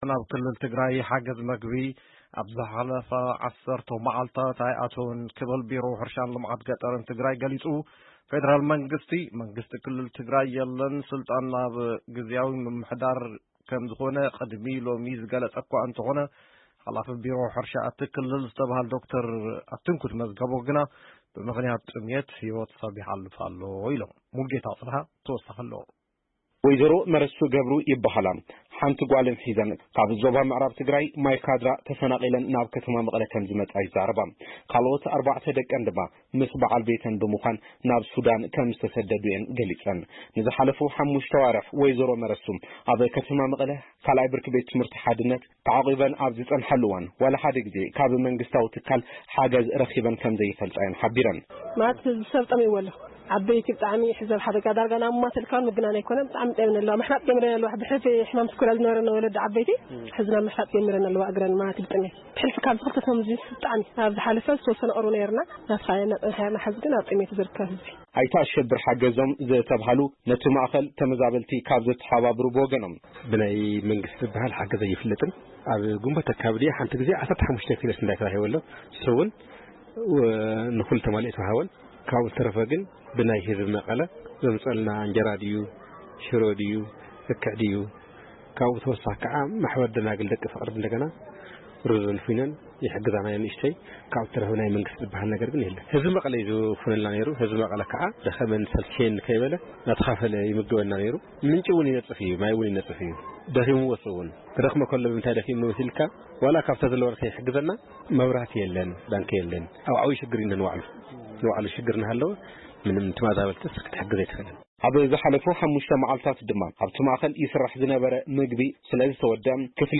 ብመሰረት እቲ ጸብጻብ፡ እቲ ውሑድ ክመጽእ ዝጸንሐ ሓገዝ እኽሊ ኣቋሪጹ፡ ኣብ መኻዚኖ ዝጸንሐ ዝርካቡ ቀረባት’ውን ብምውድኡ ህዝቢ ኣደዳ ከቢድ ጥሜት ኮይኑ ከም ዘሎ ተፈሊጡ ኣሎ። እቲ ዝተላእከ ጸብጻብ ንዝምልከቶም ሓለፍቲን ተመዛበልቲ ህዝቢን ኣዘራሪቡ’ዩ ዘሎ፡ ምሉእ ጸብጻብ ኣብዚ ምስማዕ ይክኣል።